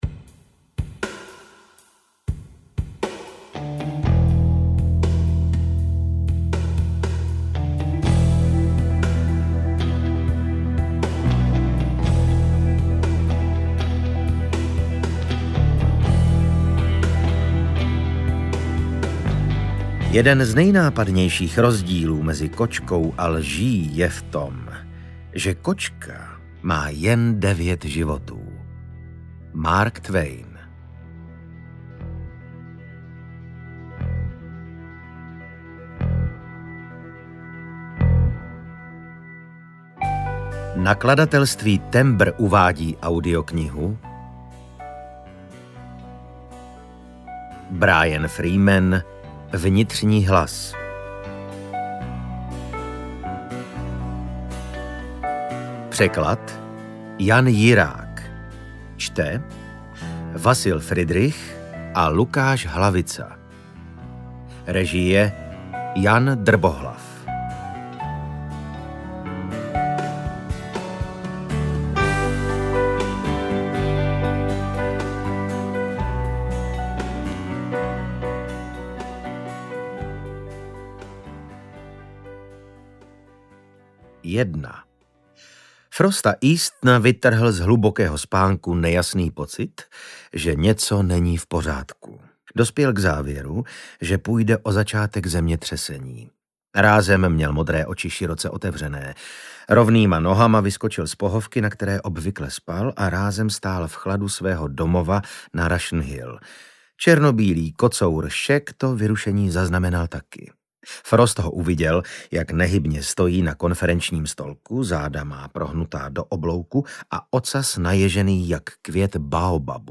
Čte: Vasil Fridrich a Lukáš Hlavica
audiokniha_vnitrni_hlas_ukazka.mp3